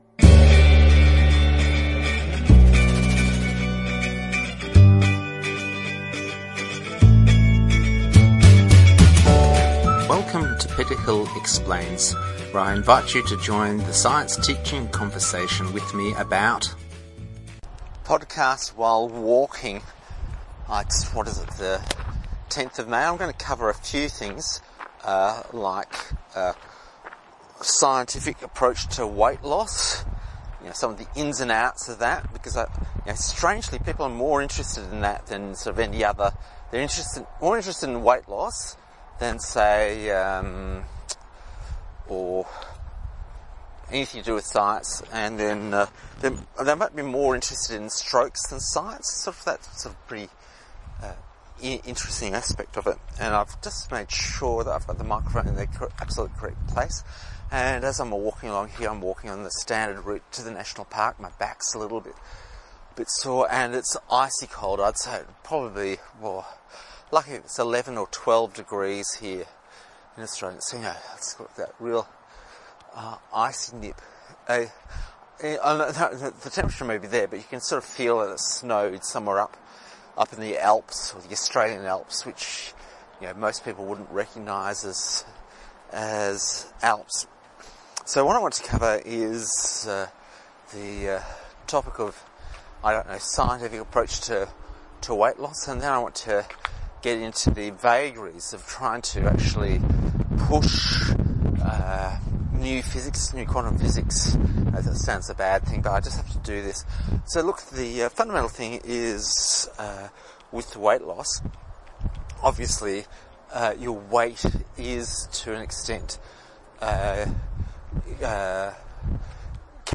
General discussion of a tracked weight loss, and then insane ideas being taught to children and the people committed to do so. My aim is merely to keep mental active on a rather though 90 min walk to and from Glenbrook Gorge.